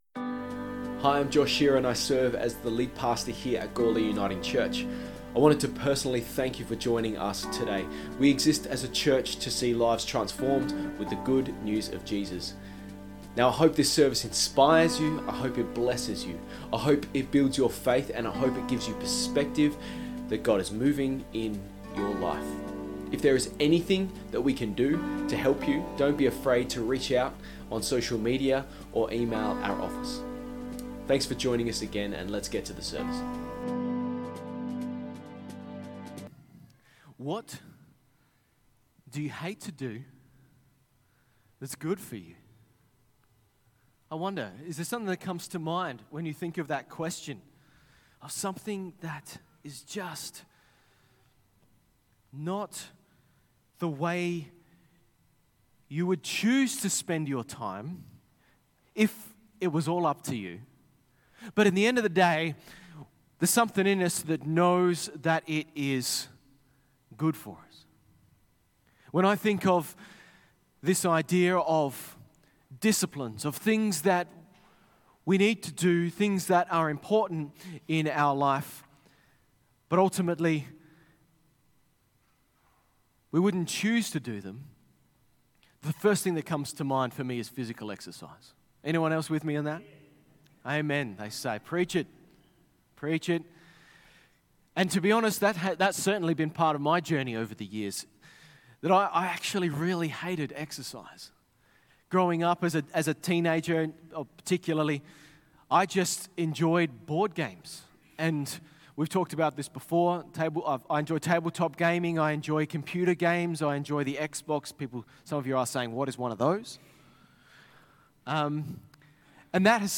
Sermons | Transform Uniting Church